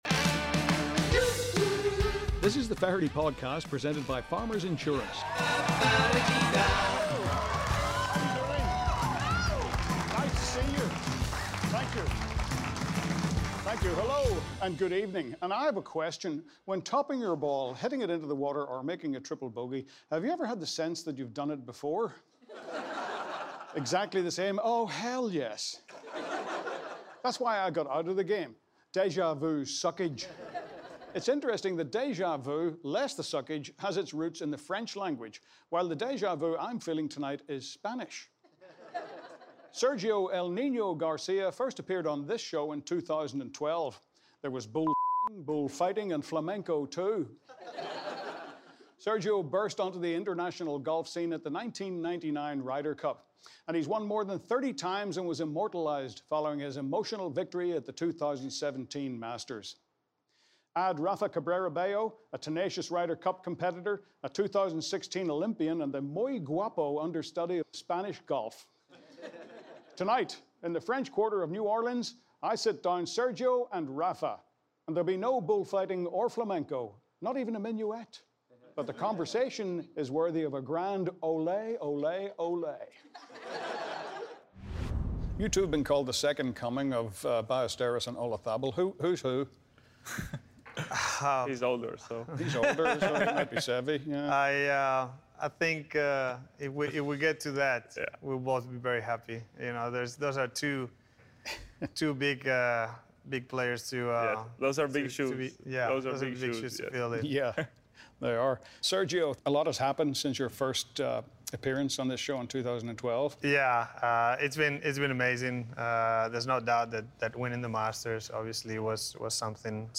The Spaniards are coming! Feherty travels to New Orleans for a conversational fiesta with 2017 Masters champion Sergio Garcia and three-time European Tour winner Rafa Cabrera Bello.